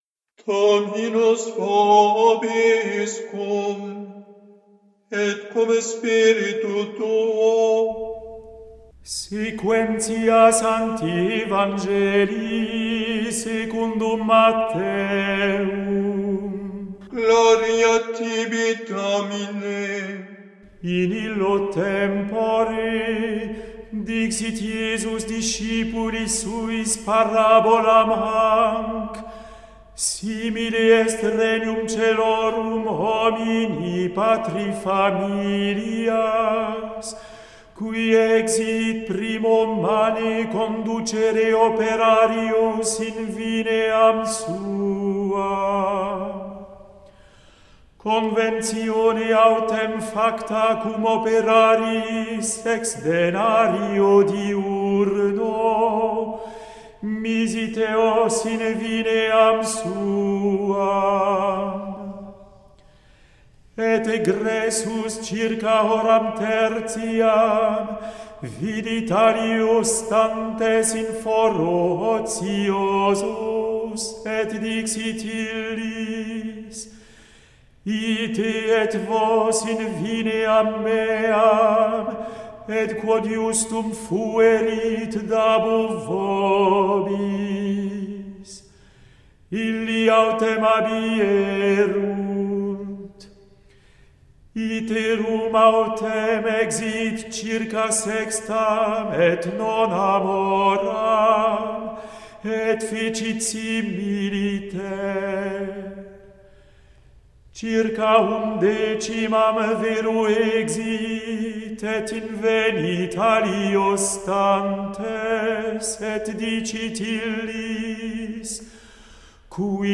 Evangelium